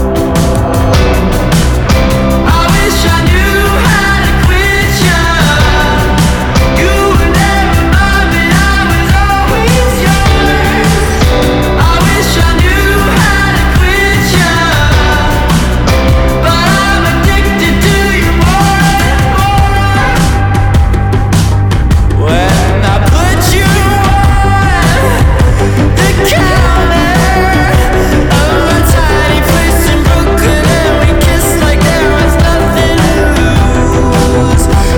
Жанр: Рок / Альтернатива
Indie Rock, Alternative